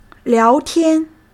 liao2-tian1.mp3